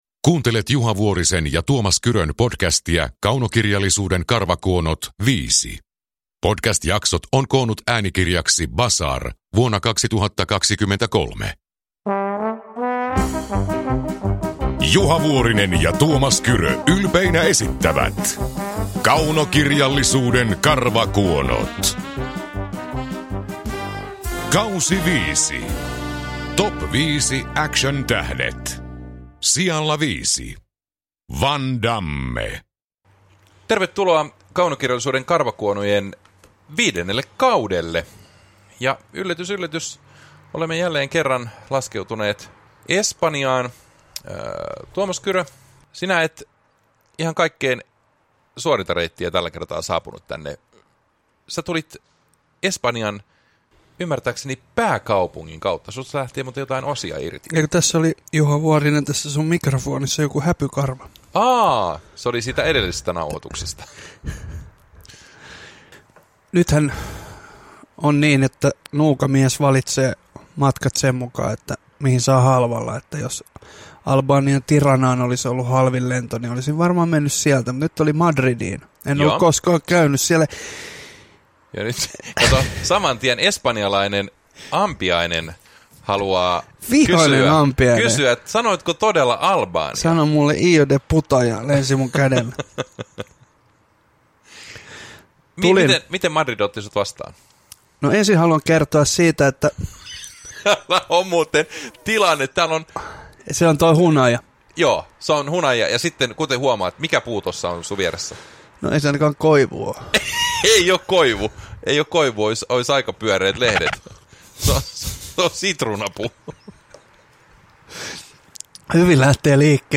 Kaunokirjallisuuden karvakuonot K5 – Ljudbok
Uppläsare: Tuomas Kyrö, Juha Vuorinen